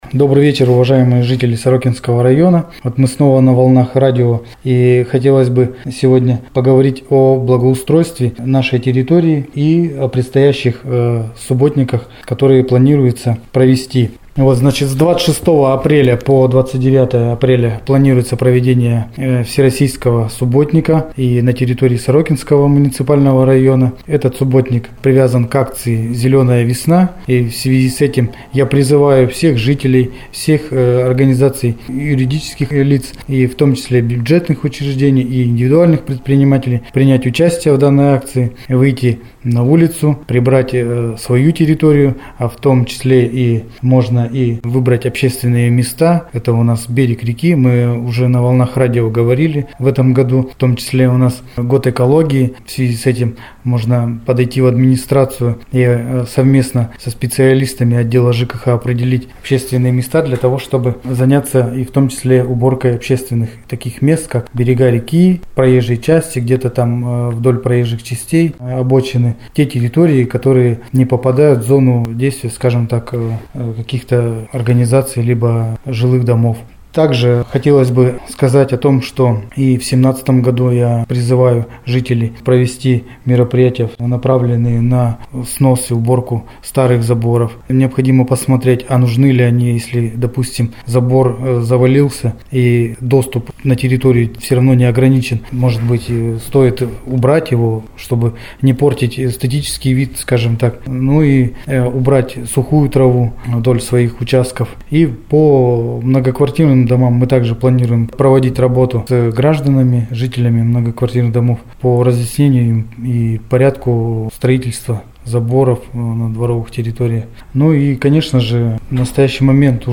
О проведении экологического субботника в рамках Всероссийской акции "Зеленая Весна" рассказал заместитель главы администрации Сорокинского МР, начальник отдела ЖКХ Полеваев А.М